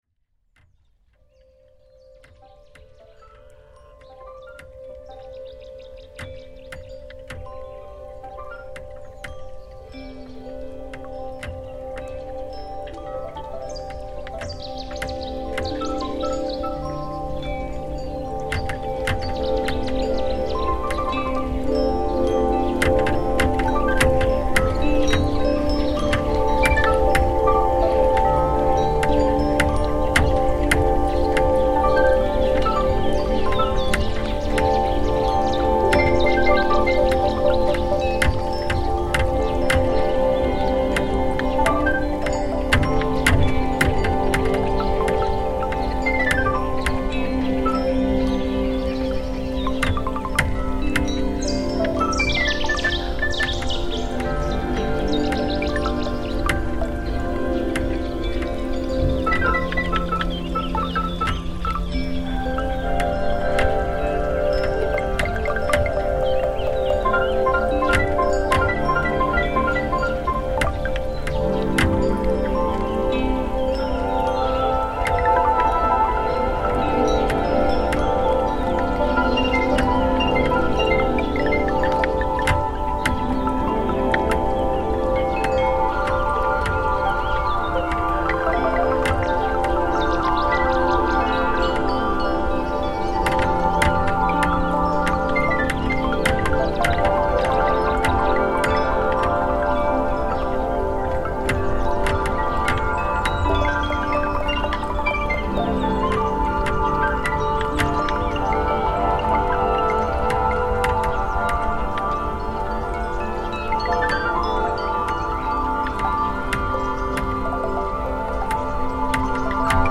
Floating dock at Lake Chiusi